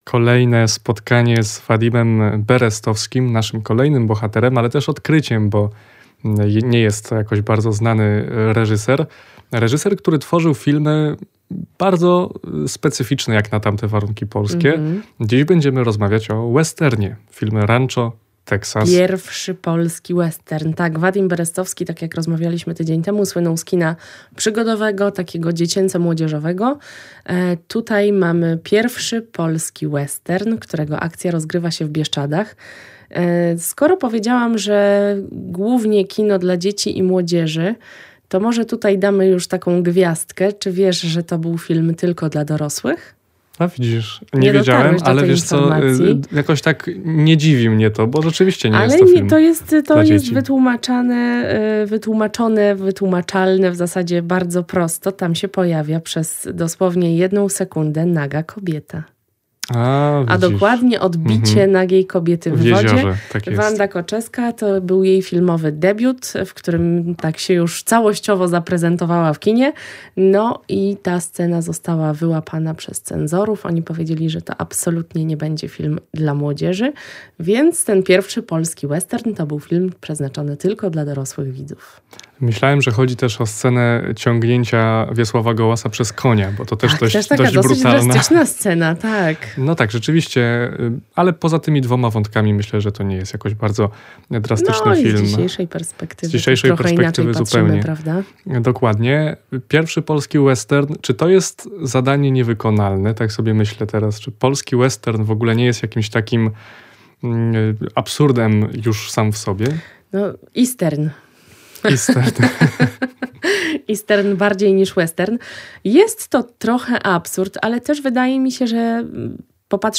„Rancho Texas” – o pierwszym polskim westernie i jego wpływie na społeczeństwo rozmawiają dziś